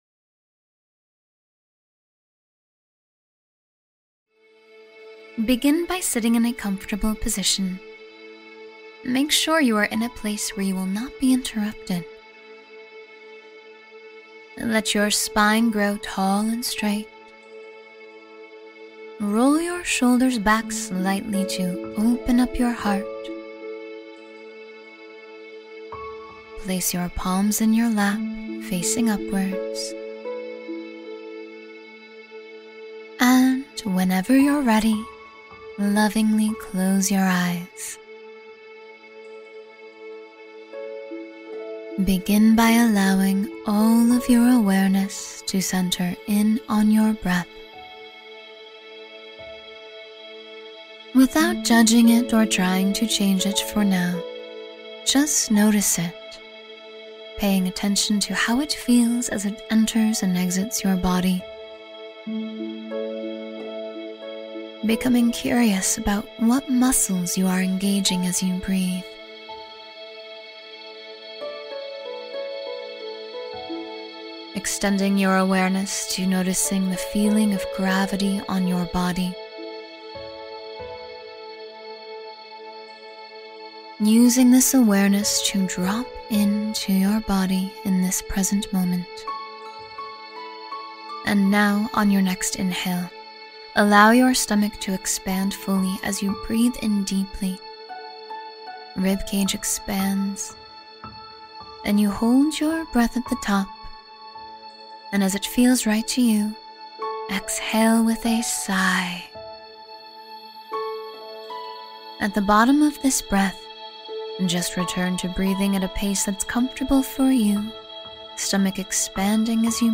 Become the Best Version of Yourself — Meditation for Personal Growth